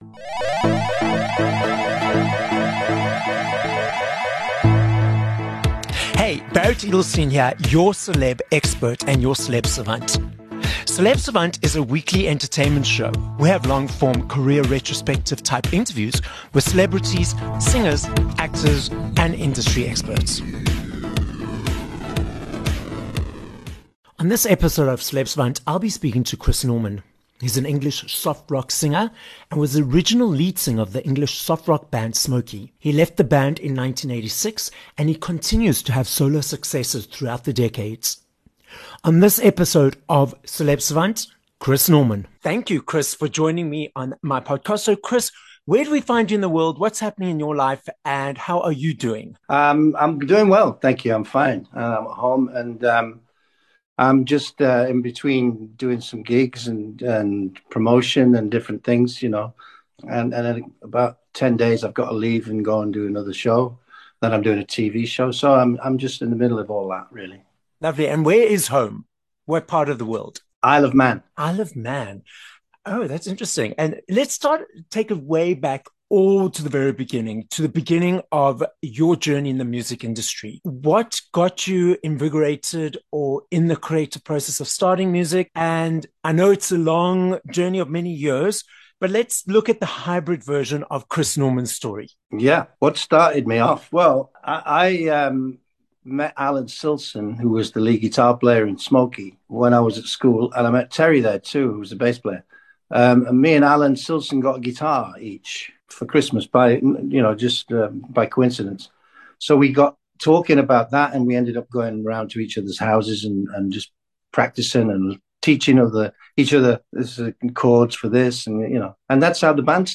16 Nov Interview with Chris Norman